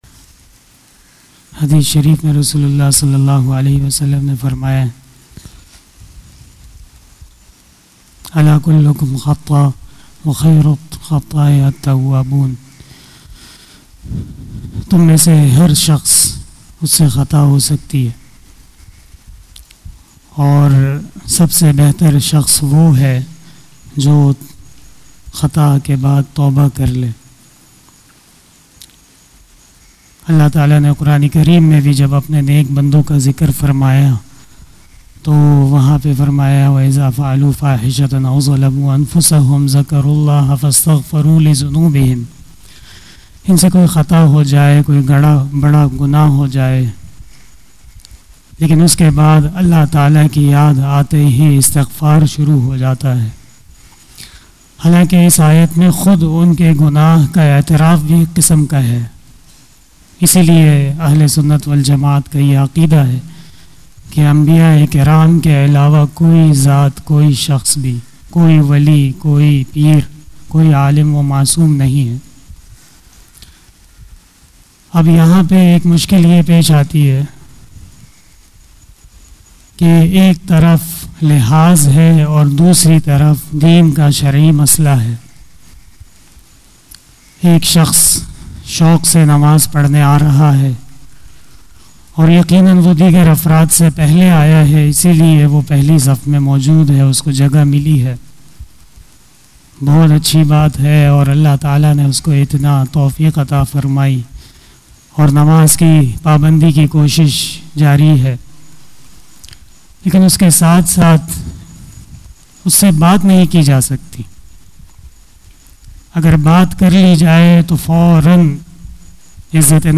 After Asar Namaz Bayan
بیان بعد نماز عصر